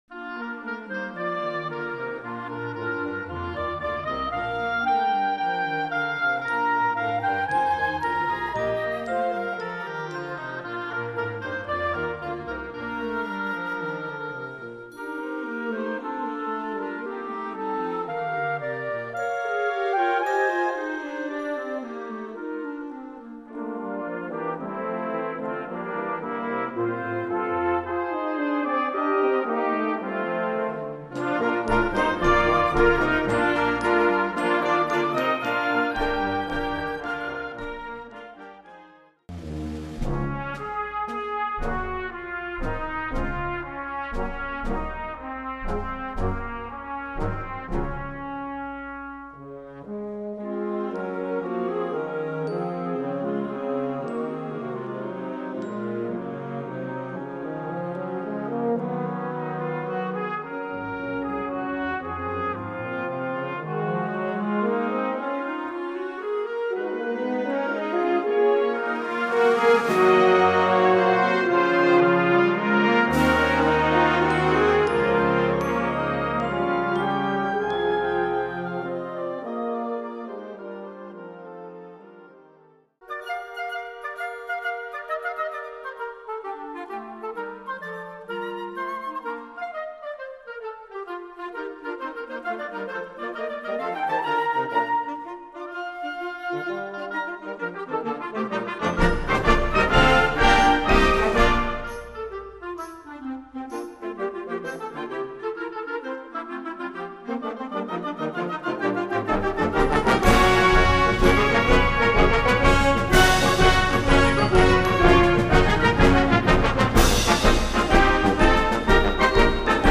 Gattung: Suite
Besetzung: Blasorchester